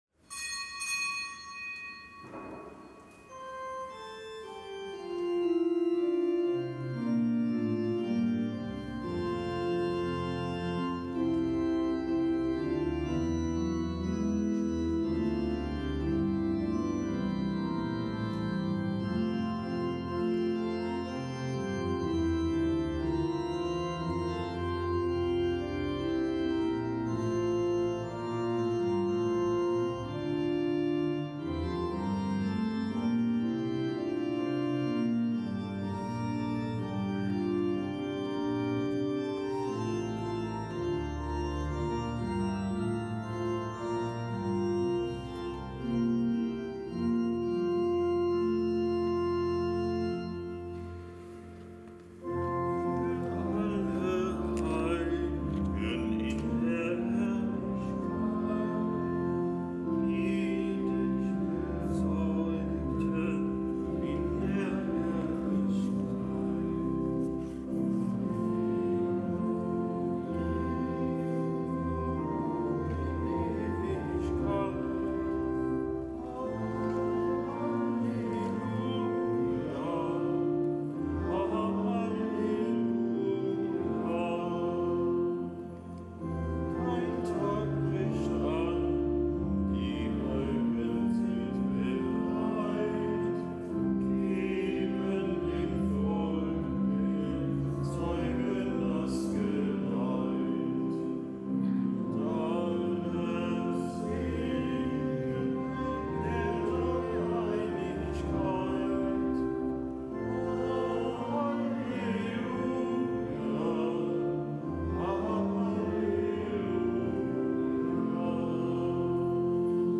Kapitelsmesse aus dem Kölner Dom am Dienstag der neunundzwanzigsten Woche im Jahreskreis.